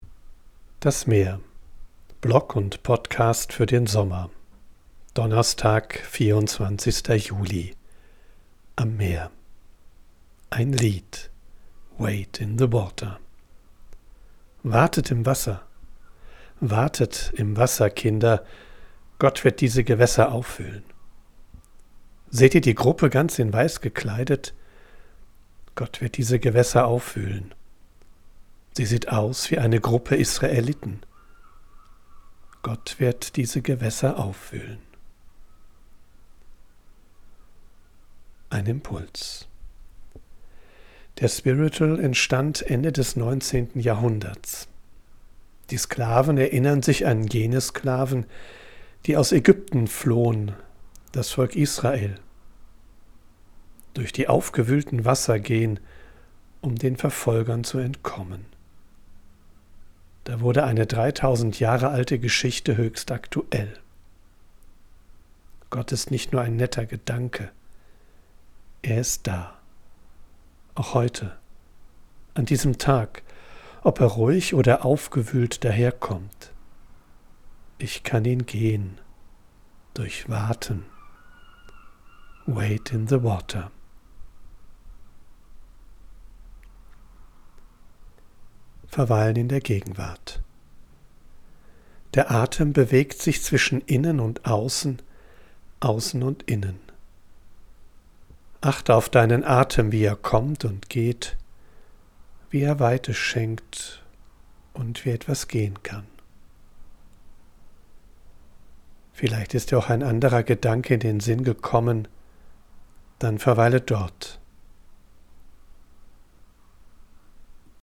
live. Ich bin am Meer und sammle Eindrücke und Ideen. Weil ich
von unterwegs aufnehme, ist die Audioqualität begrenzt. Dafür
mischt sie mitunter eine echte Möwe und Meeresrauschen in die